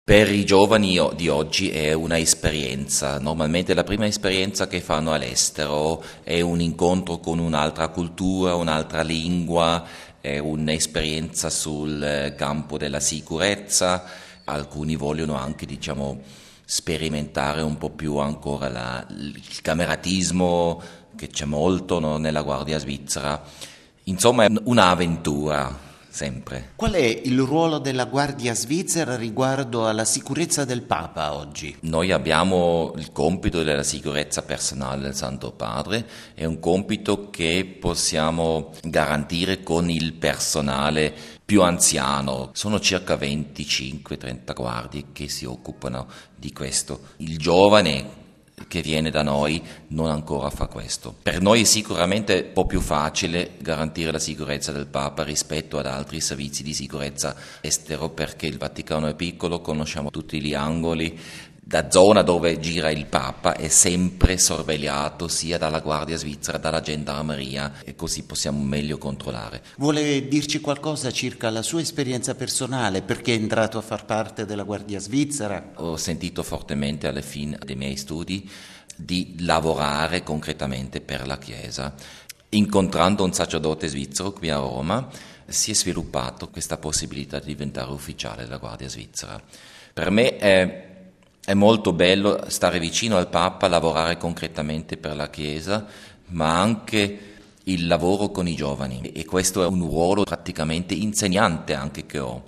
Le Guardie Svizzere commemorano i caduti nella difesa del Papa durante il Sacco di Roma, il 6 maggio del 1527. Intervista